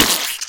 attack.mp3